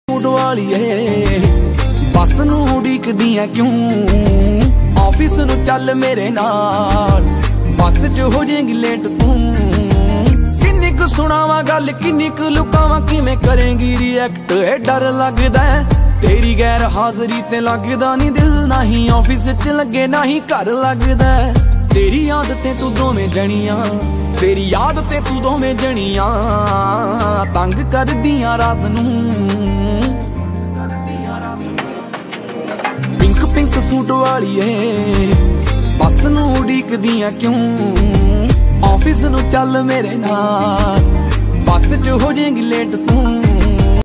punjabi ringtone